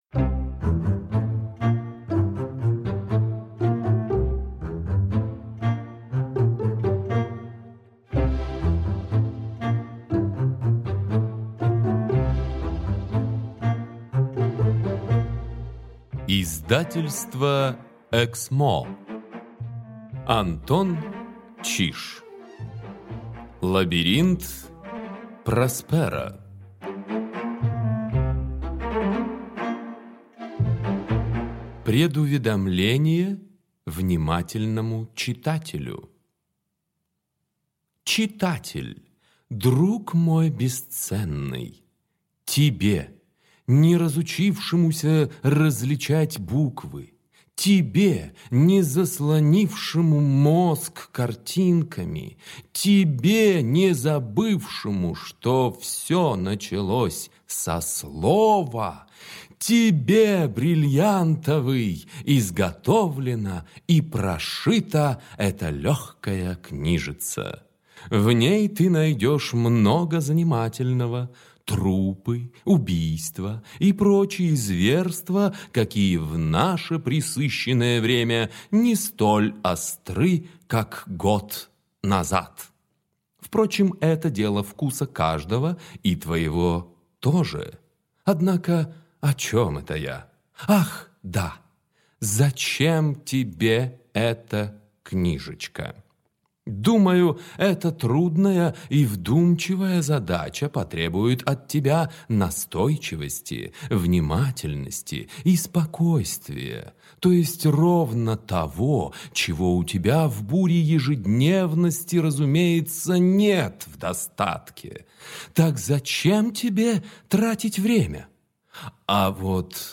Аудиокнига Лабиринт Просперо | Библиотека аудиокниг